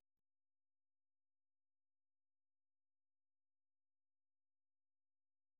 Форма симфоническая поэма
Произведение написано в тональности ре минор и является примером ранних тональных работ Шёнберга.
Темы Мелизанды в поэме основаны на мотиве из трёх нот: